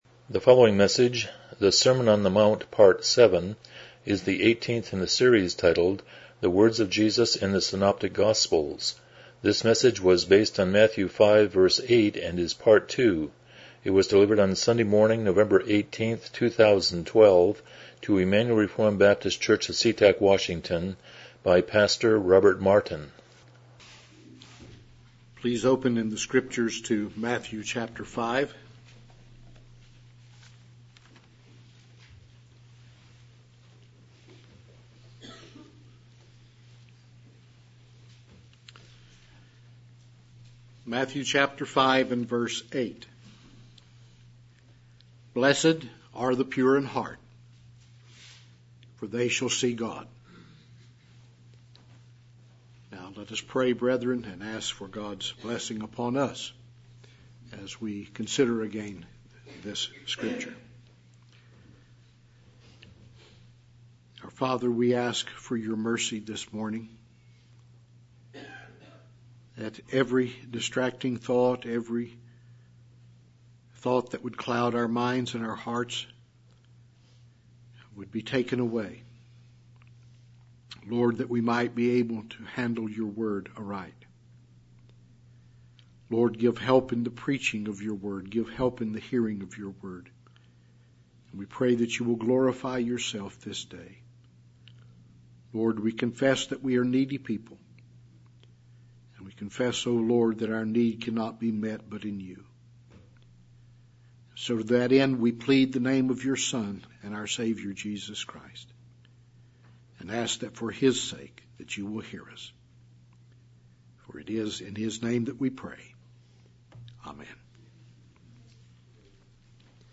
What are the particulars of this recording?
Passage: Matthew 5:8 Service Type: Morning Worship